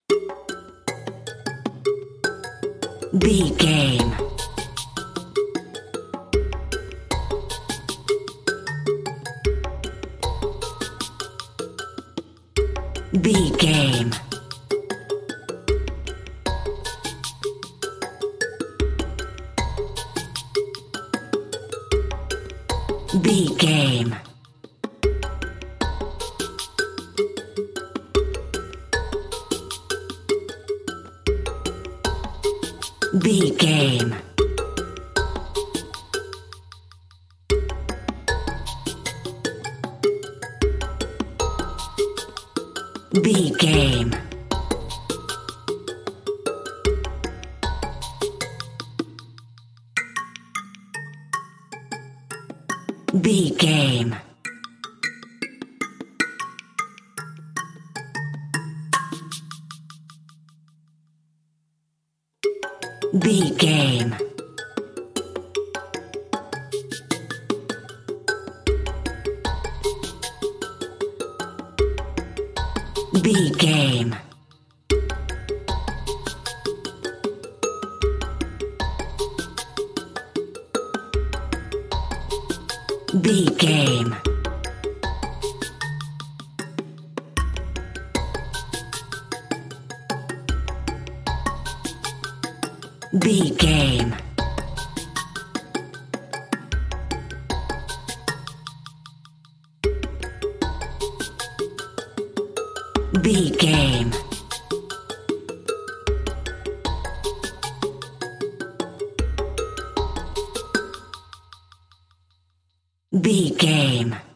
Atonal
bongos
congas
hypnotic
medium tempo